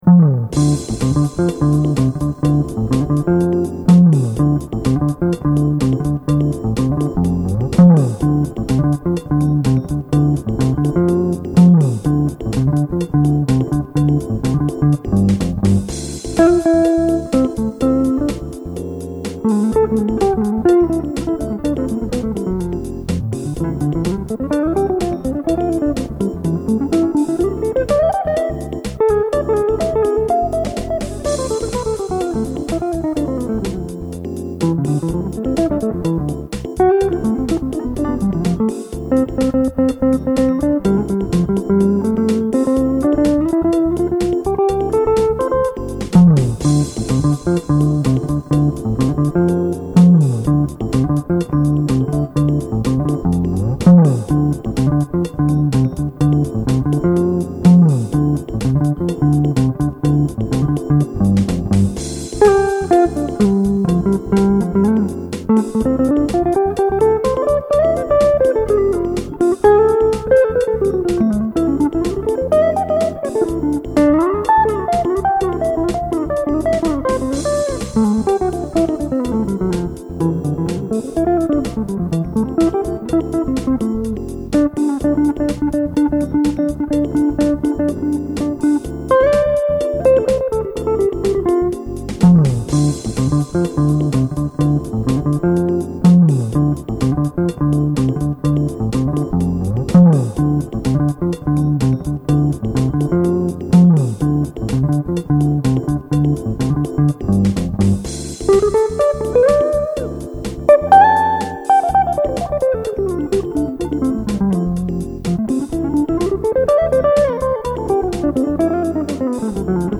Choplicity groove original sums up some of my progress to date.